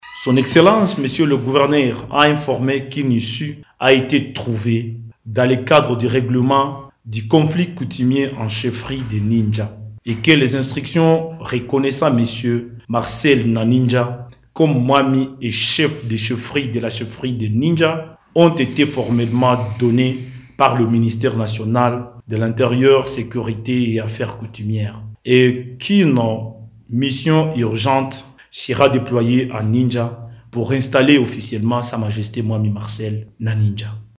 Dans le même volet, Jean Jacques Purusi Sadidki a rassuré que les travaux de construction du tronçon Bukavu-Kamanyola avancent bien et que les entreprises pour la construction de la route Kamanyola-Uvira sont déjà connues, a poursuivi le porte-parole du gouvernement lisant le compte du conseil des ministres.